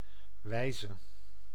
Ääntäminen
US GenAm: IPA : /ˈmɛlədi/ RP : IPA : /ˈmelədi/